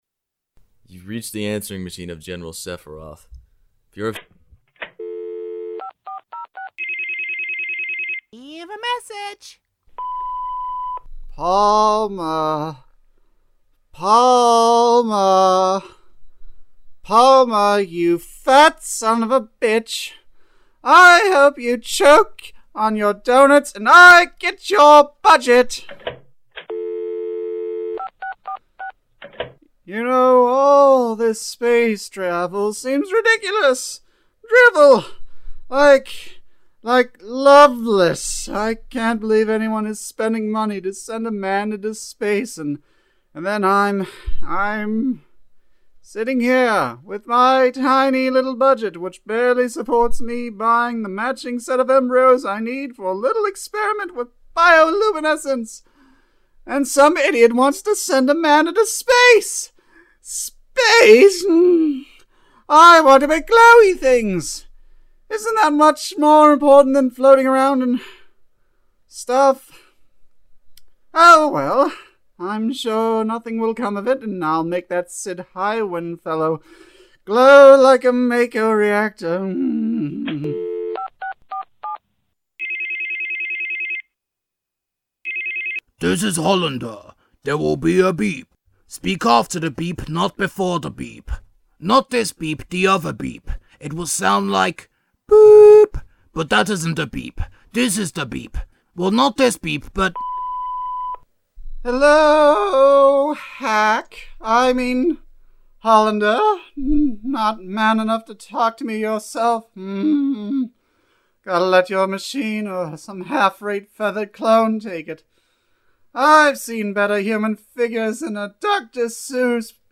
Audio Dramas